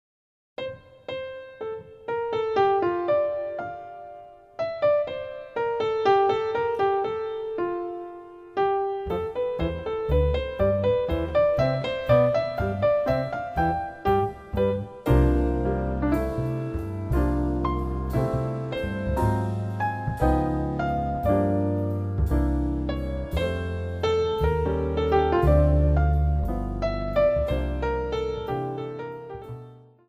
將古典大師的作品改編成爵士三重奏的型式，讓高雅的 古典曲目增添了輕快寫意的風味